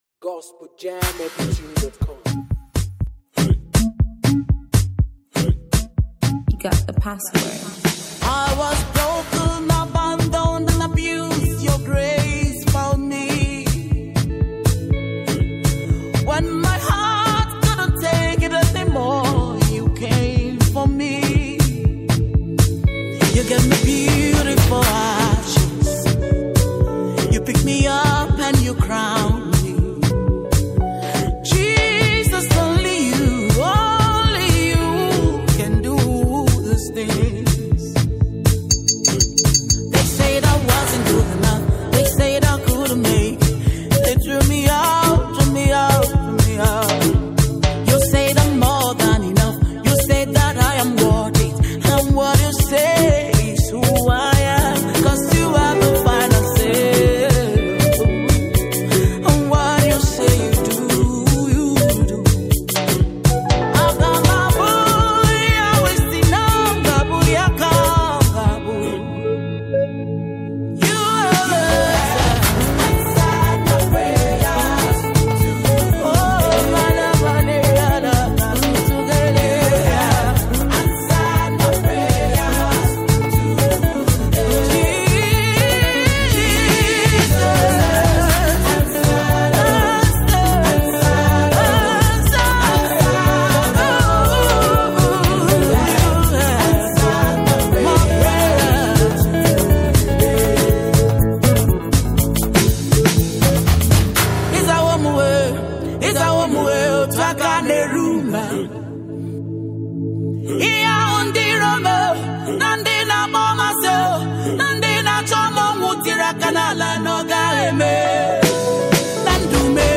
African praiseAfro beatmusic